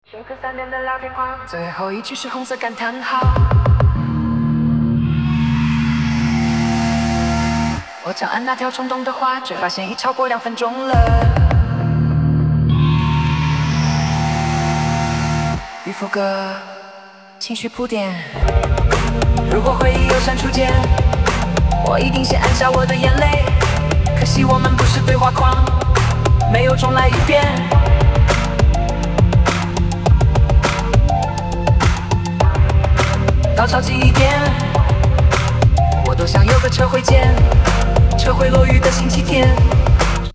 人工智能生成式歌曲